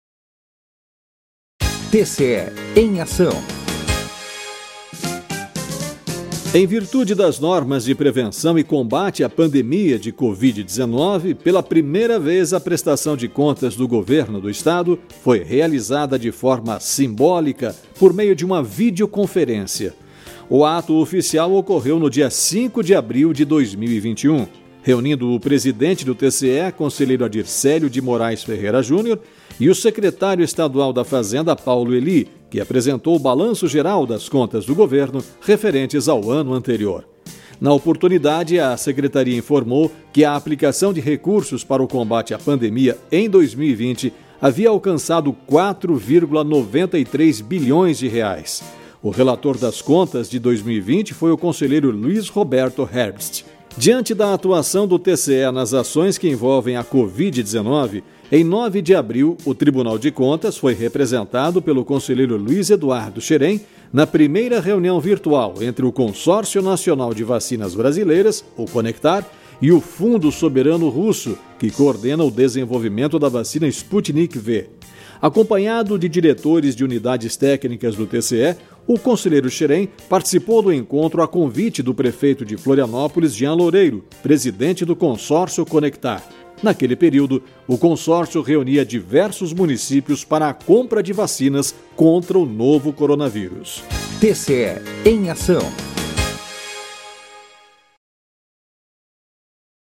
VINHETA – TCE EM AÇÃO